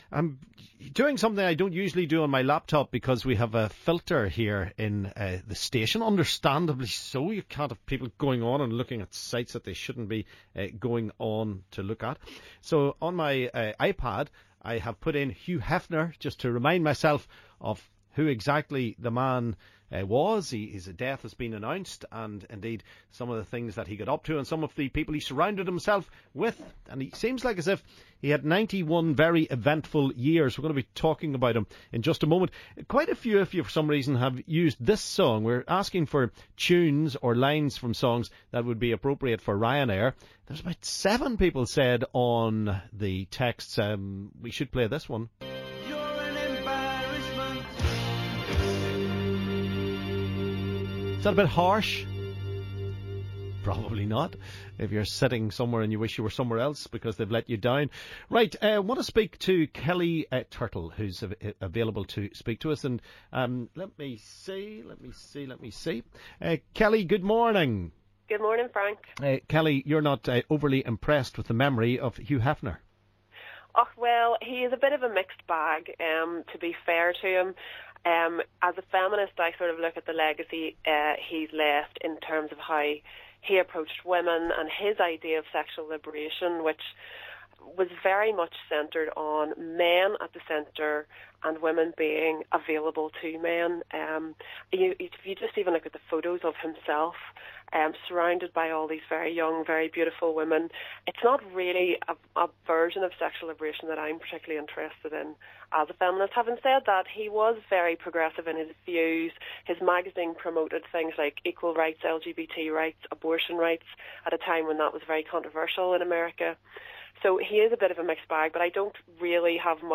We hear from a feminist, a former glamour model and our listeners.